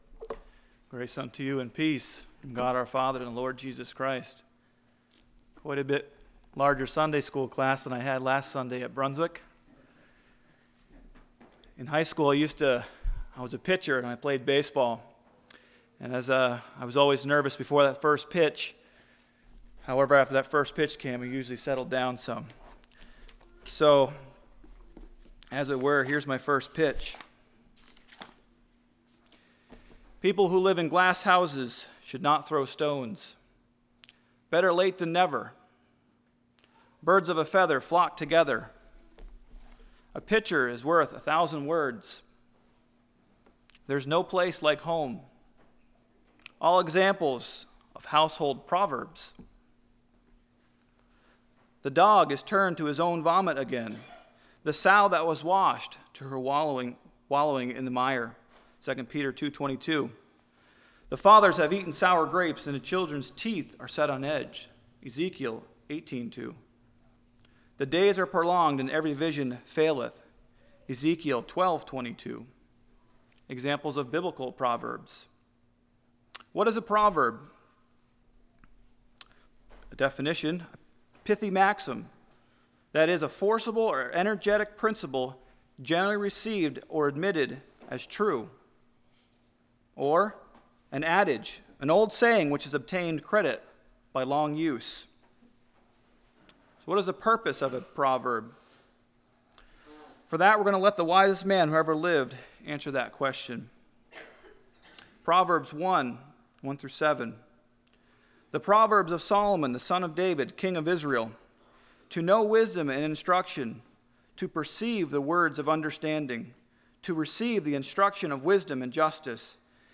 Fall Revival 2013 Passage: Galatians 6:17-10 Service Type: Sunday School Deceived Weary Opportunity « Do You Remember Baptism